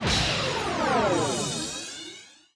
tree_hit_by_fireball.mp3